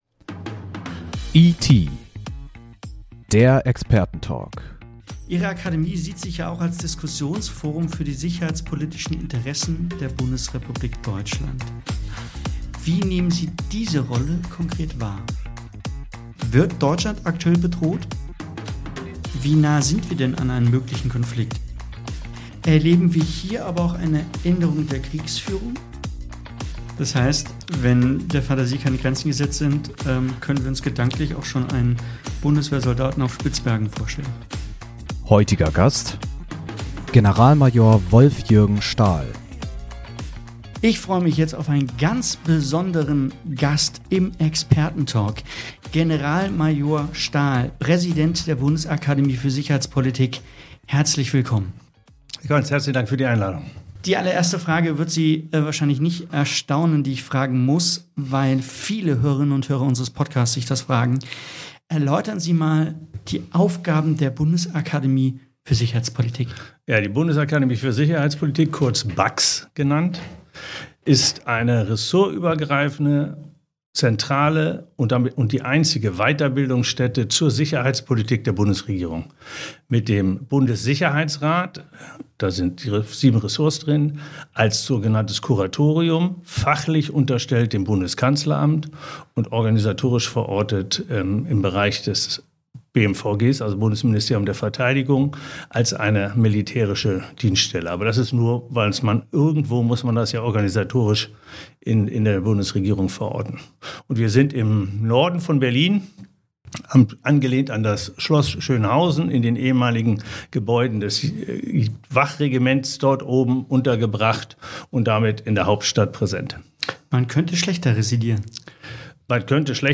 Hören Sie hier die 1. Folge des Rotary-Magazin-Podcasts "ET – Der ExpertenTalk" mit Generalmajor Wolf-Jürgen Stahl über die Sicherheitslage in der Bundesrepublik Deutschland: